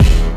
drebass.wav